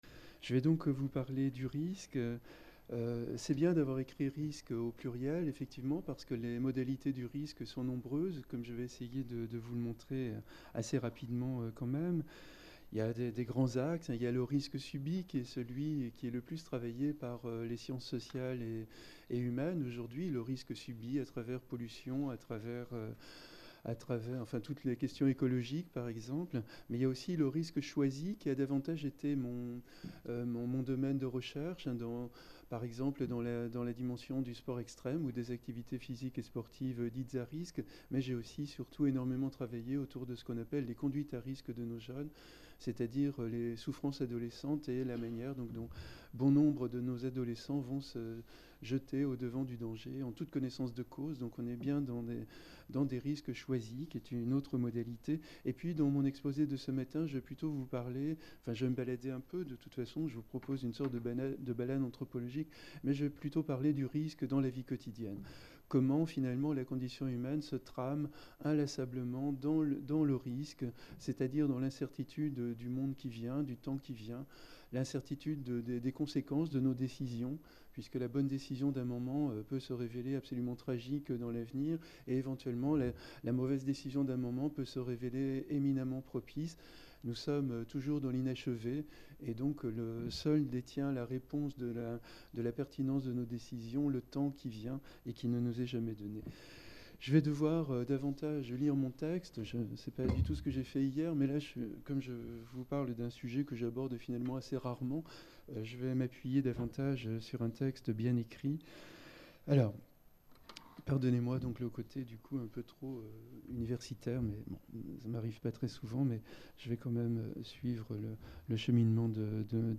Cette conférence d’ouverture a été filmée lors de la 2ème journée de l'École doctorale 556 HSRT, organisée à la Maison de la Recherche en Sciences Humaines de l'Université Caen Normandie.